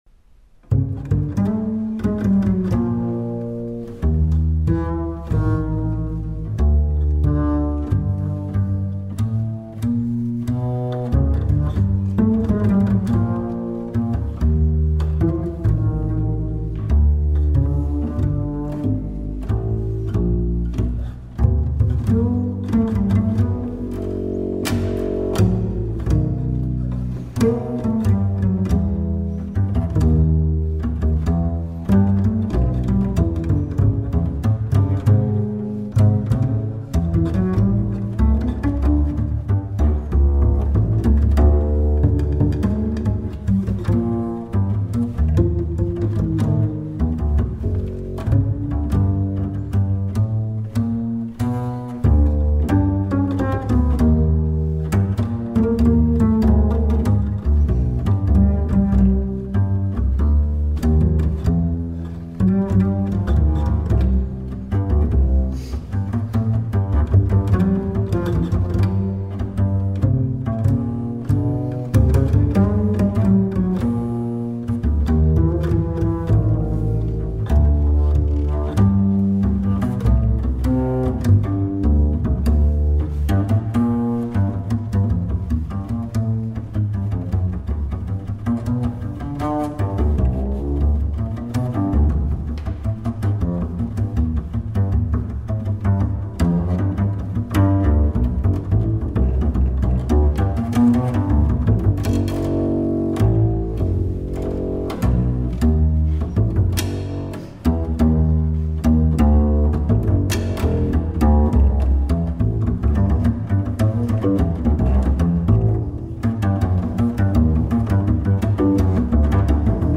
upright bass